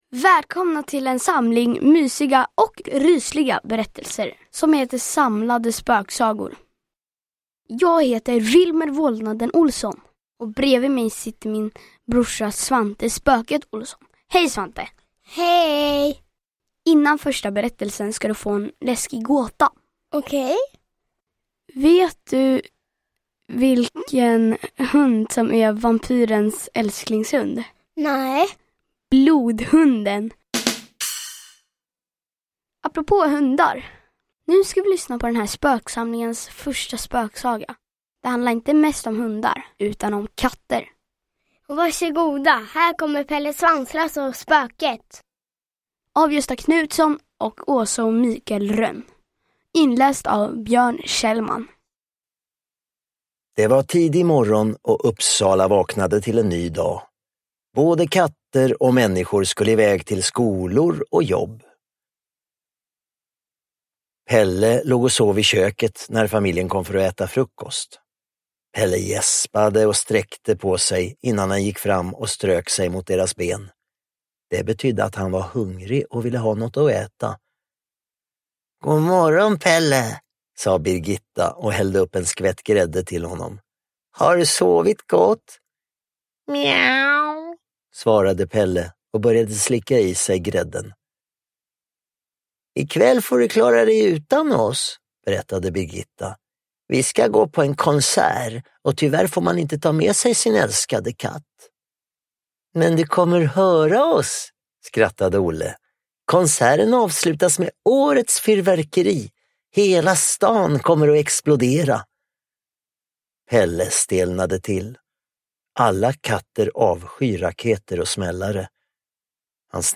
En härlig ljudbokssamling för alla små spök- och monsterdiggare!
Pelle Svanslös och spöket – inläst av Björn Kjellman